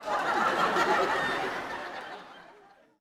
Audience Laughing-03.wav